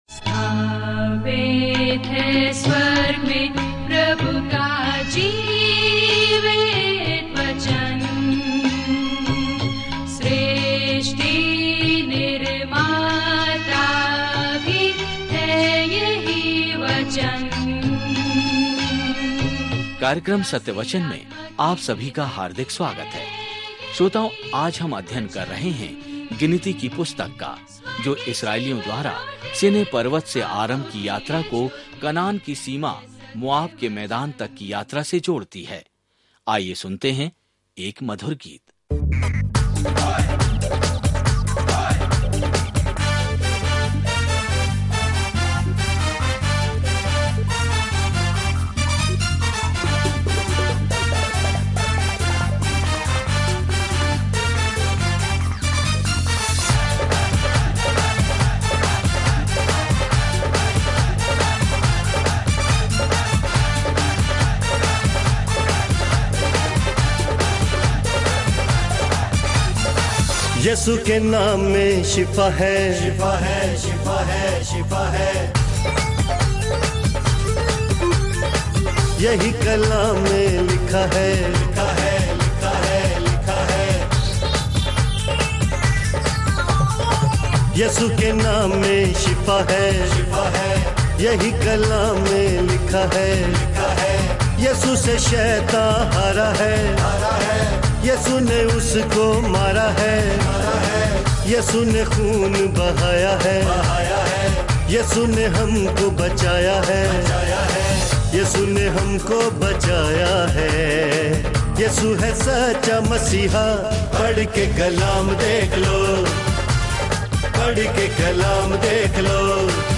पवित्र शास्त्र गिनती 28:3-31 गिनती 29 गिनती 30 दिन 17 यह योजना प्रारंभ कीजिए दिन 19 इस योजना के बारें में संख्याओं की पुस्तक में, हम जंगल में 40 वर्षों में इज़राइल के साथ चल रहे हैं, भटक रहे हैं और पूजा कर रहे हैं। जैसे ही आप ऑडियो अध्ययन सुनते हैं और भगवान के वचन से चुनिंदा छंद पढ़ते हैं, संख्याओं के माध्यम से दैनिक यात्रा करें।